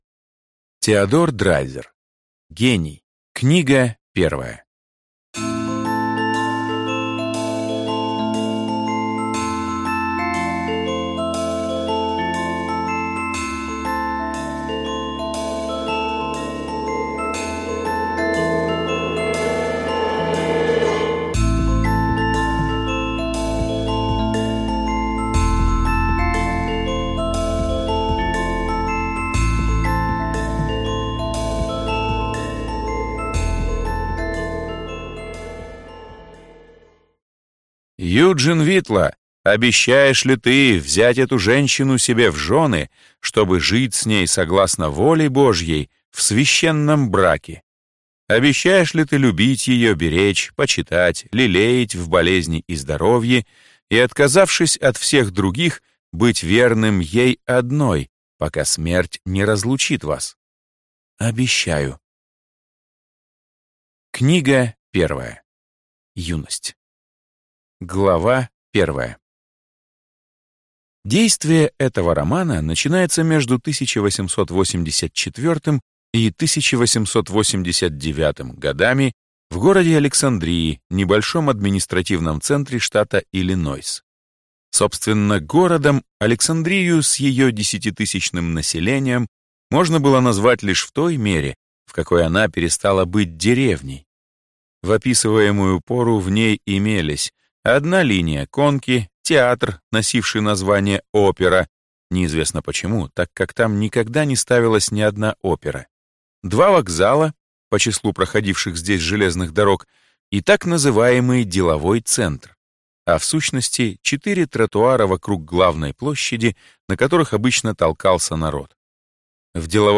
Аудиокнига Гений. Книга 1 | Библиотека аудиокниг